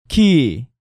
Category:Hangeul sounds